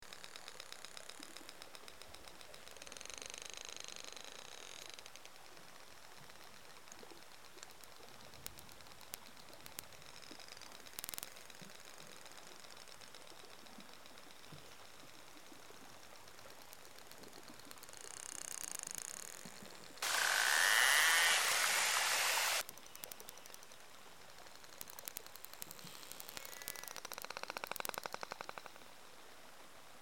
超音波も含まれているので、聴き取れるか試してみましょう。
※イッカクの音（グリーンランドの海域で録音されたもの）
しかし、信号の高周波部分を見ると、クジラの声が聞こえます。
Narwhal.mp3